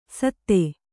♪ satte